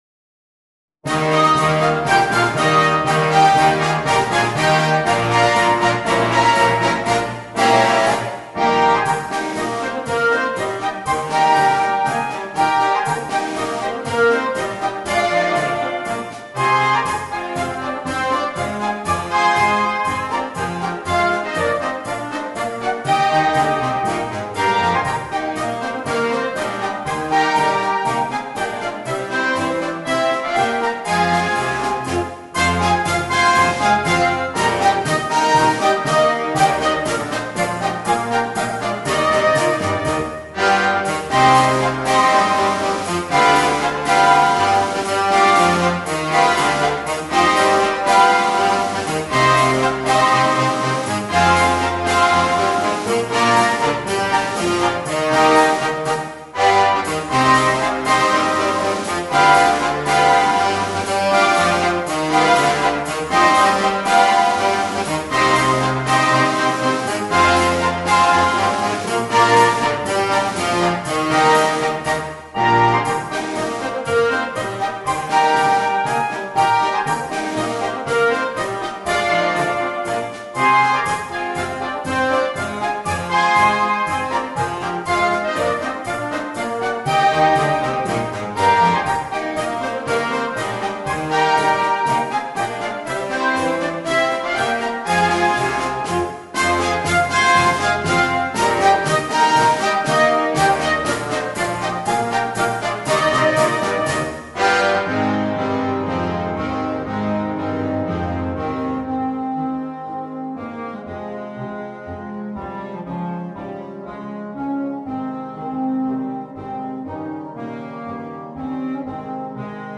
Una bella e facile marcia scaricabile anche gratuitamente
MARCE DA SFILATA
MUSICA PER BANDA